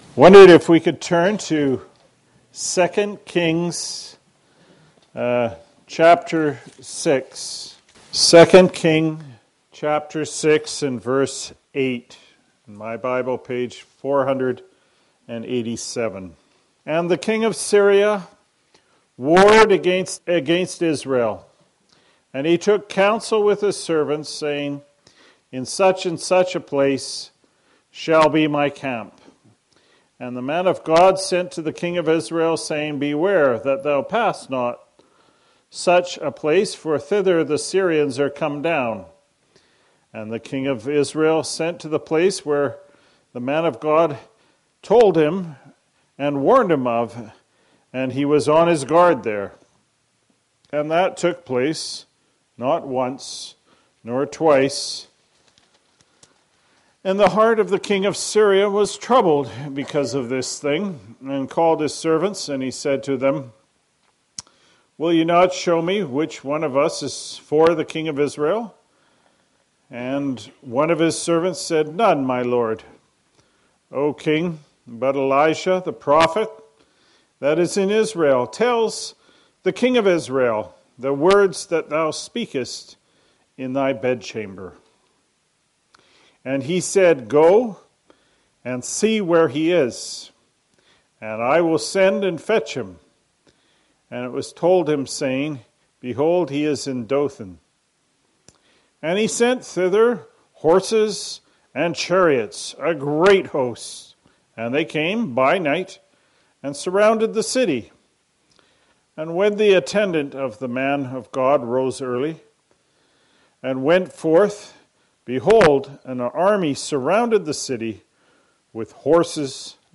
Bible Teaching (Addresses)